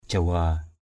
/ʥa-wa:/ (d.) Java.